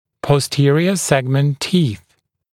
[pɔs’tɪərɪə ‘segmənt tiːθ][пос’тиэриэ ‘сэгмэнт ти:с]зубы бокового сегмента (-ов)